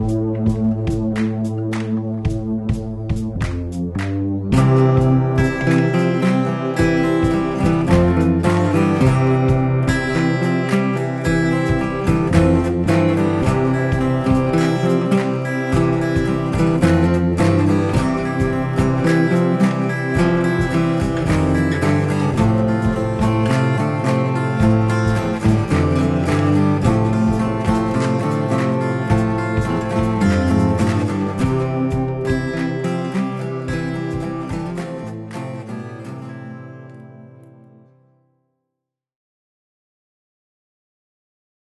I selected an audio drum track and tapped, onto the little square pads on the keyboard, a simple 2 part bass drum, side drum and finger snapping type percussion pattern of few beats onto two tracks. Third track I chose a keyboard piano chord of mood nature and held down some piano chords of who knows what! Then picked up the acoustic and strummed C and F followed by a simple plucked riff into the guitar mode of the MV5.
The playing is as basic as it comes.
Email worked, but I sent the lowest quality possible.